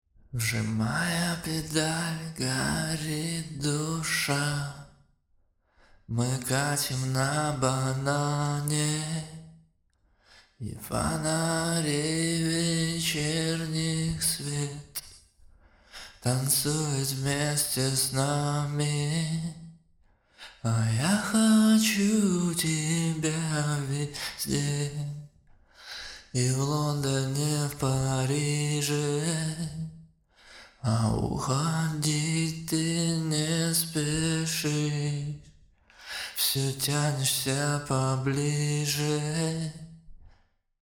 vocal.mp3